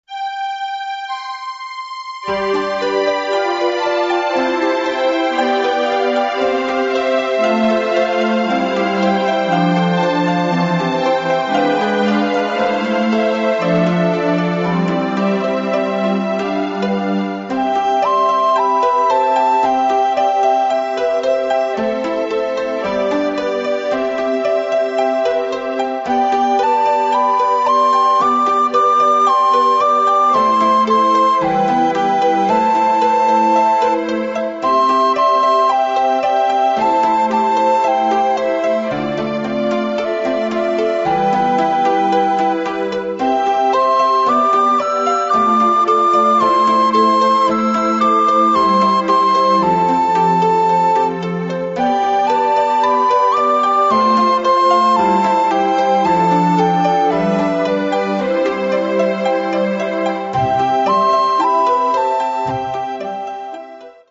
• ファイルサイズ軽減のため、音質は劣化しています。
リコーダーとシンセサイザー演奏